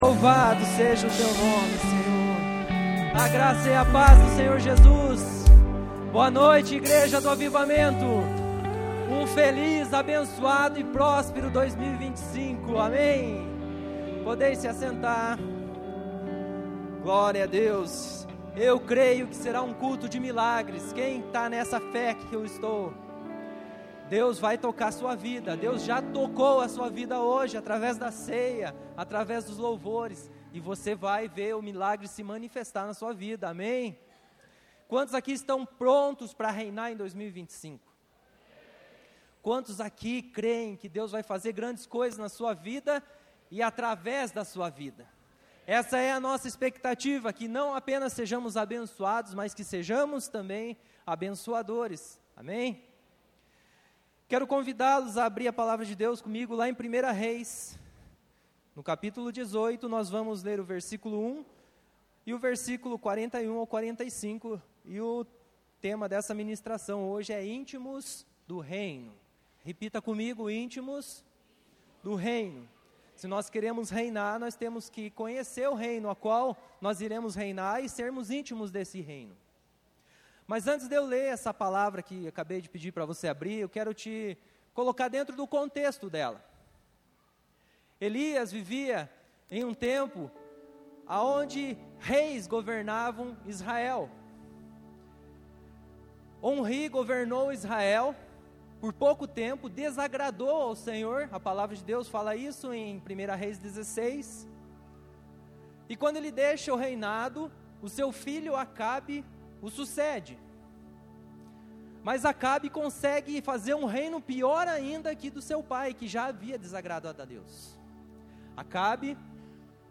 ICP - Igreja Cristã Presbiteriana